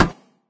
metalgrass2.ogg